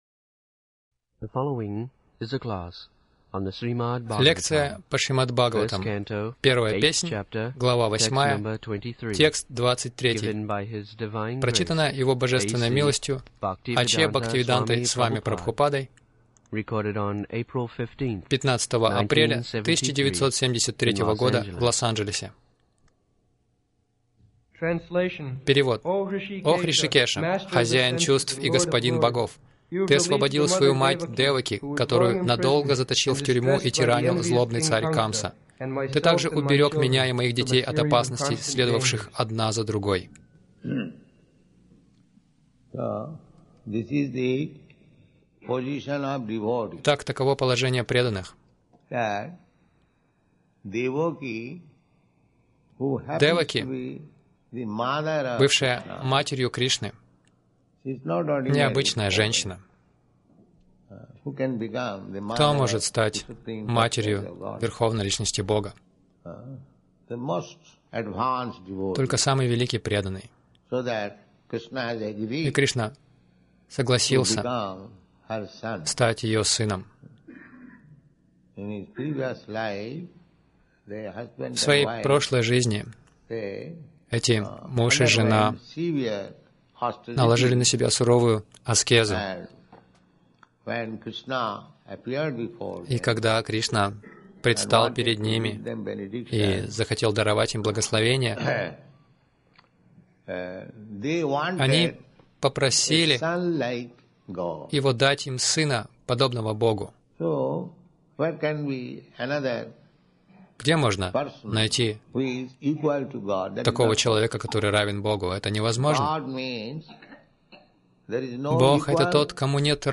Милость Прабхупады Аудиолекции и книги 15.04.1973 Шримад Бхагаватам | Лос-Анджелес ШБ 01.08.23 — Зависть — изначальное зло в материальном мире Загрузка...